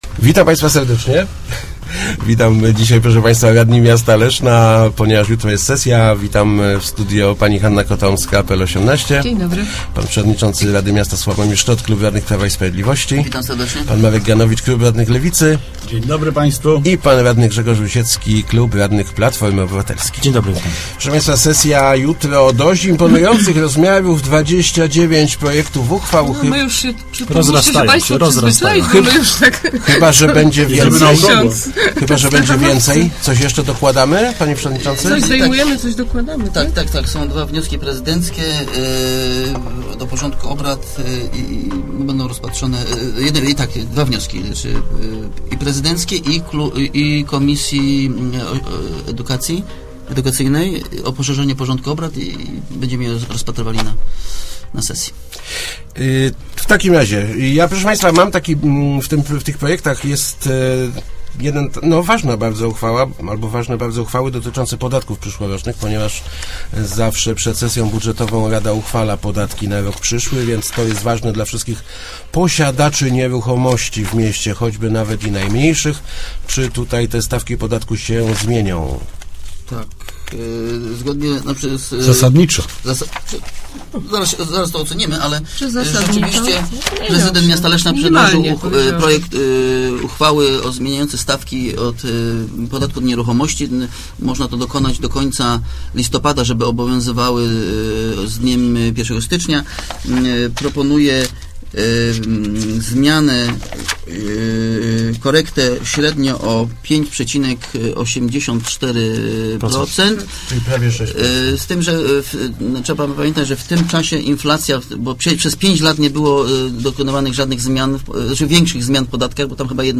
-To w�a�ciwie korekta, a nie podwy�ka – mówi� w Radiu Elka przewodnicz�cy RM S�awomir Szczot (PiS).